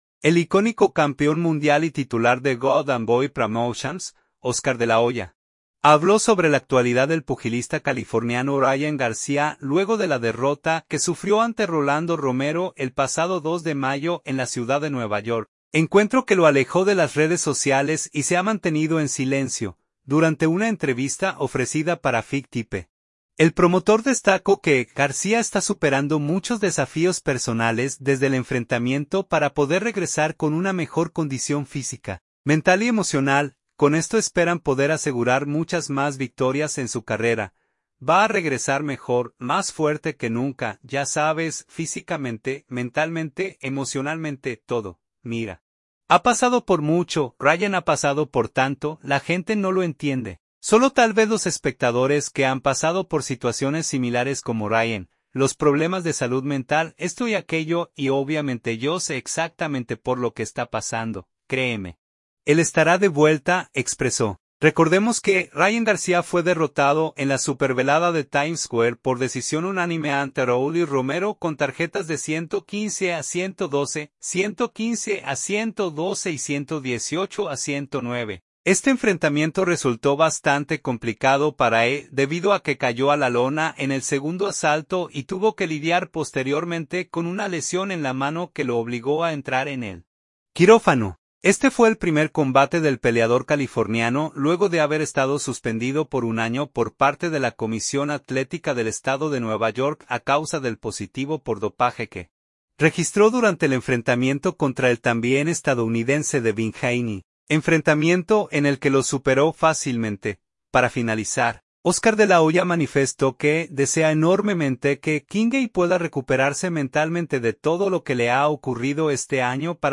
Durante una entrevista ofrecida para FightHype, el promotor destaco que García está superando muchos desafíos personales desde el enfrentamiento para poder regresar con una mejor condición física, mental y emocional; con esto esperan poder asegurar muchas más victorias en su carrera.